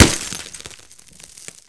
Counter-Strike Pack / Original Sounds / weapons